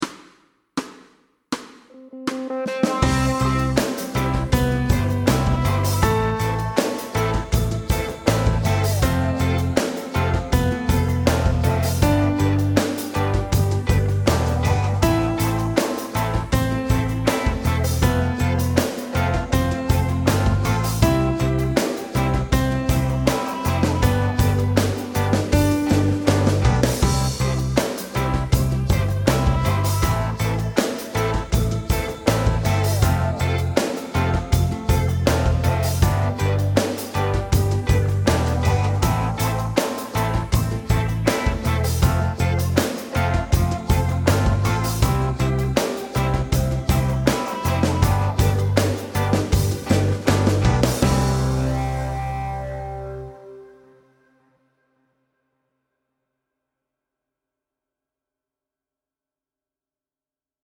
Fast C instr (demo)
Note values are whole notes, half notes and rests.